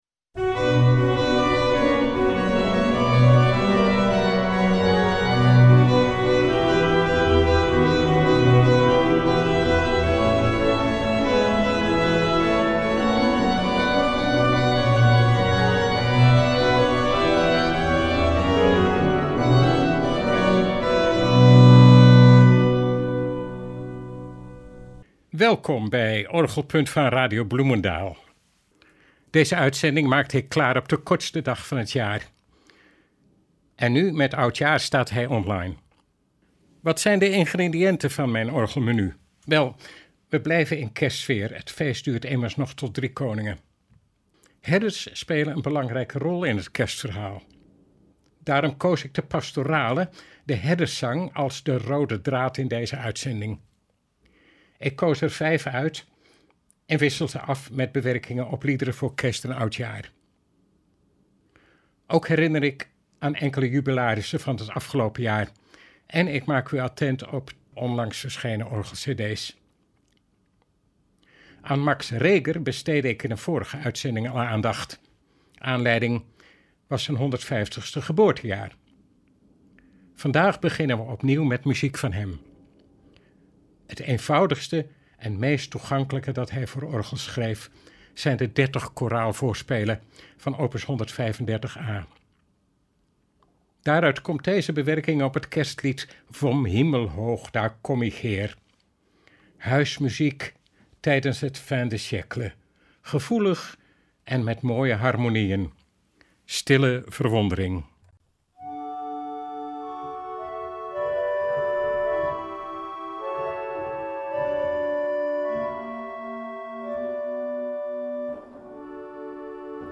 Het is een bekend en geliefd genre in de orgelmuziek.